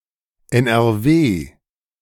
2. ^ German: [ɛnʔɛʁˈveː]
De-NRW.ogg.mp3